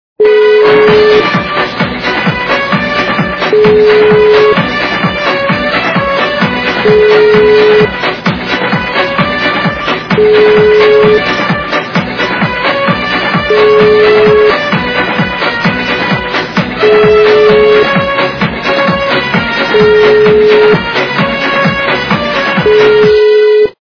западная эстрада
При заказе вы получаете реалтон без искажений.